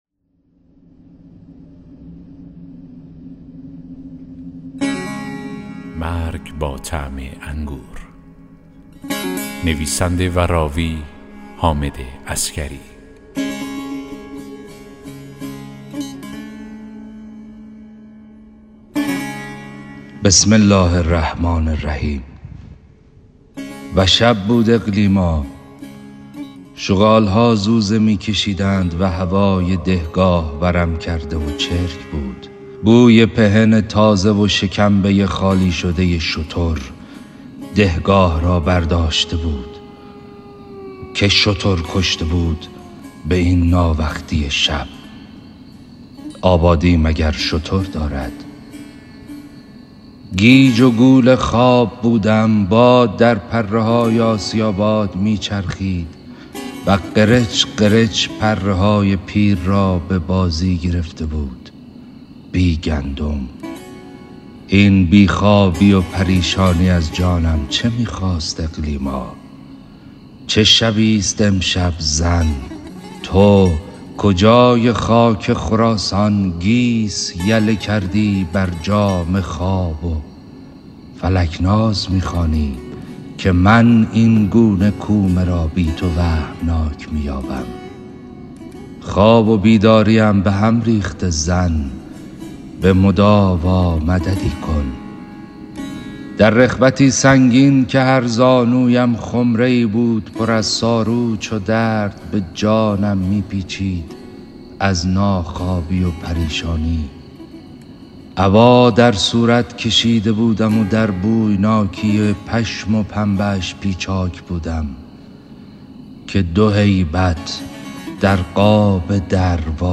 داستان صوتی: مرگ با طعم انگور